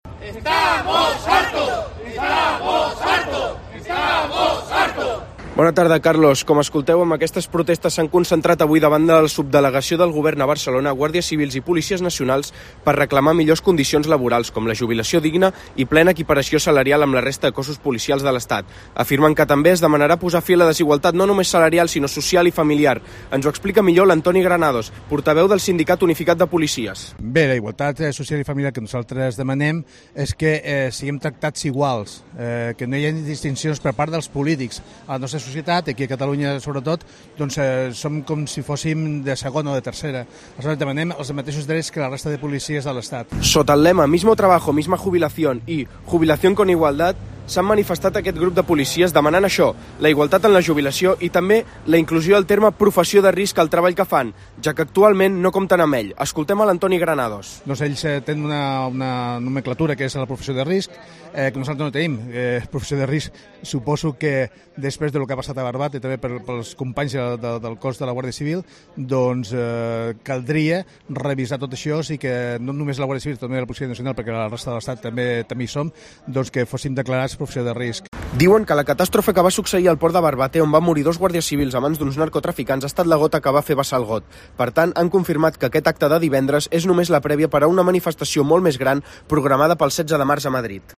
crónica
durante la manifestación